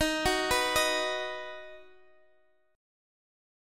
D#m#5 Chord (page 3)
Listen to D#m#5 strummed